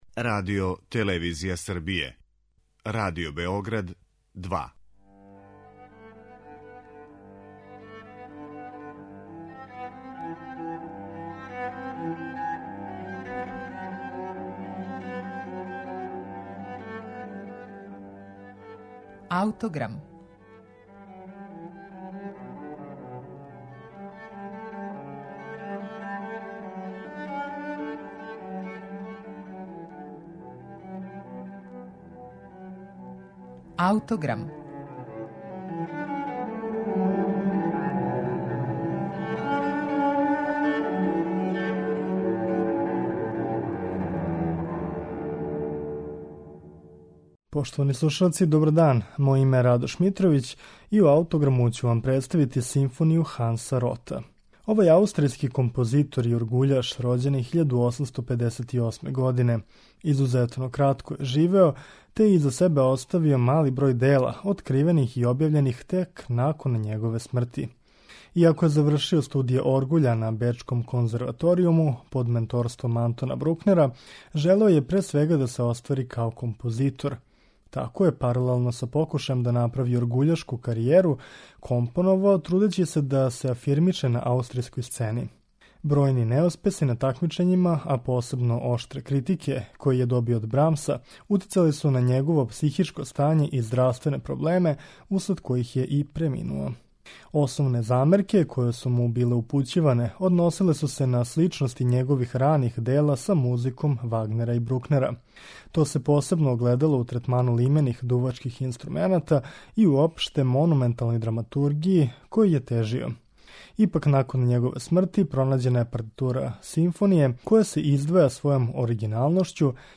Управо ову Ротову симфонију представићемо вам у Аутограму , у извођењу Симфонијског оркестра Бечког радија, под управом Дениса Расела Дејвиса.